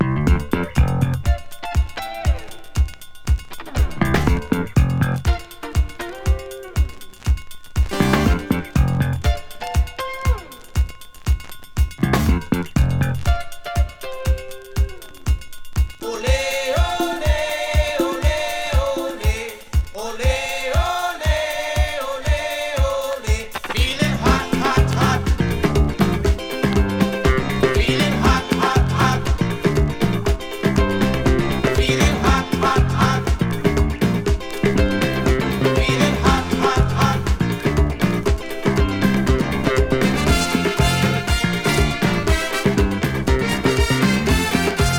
ひたすらに楽しさ溢れてます。
World, Soca　France　12inchレコード　33rpm　Stereo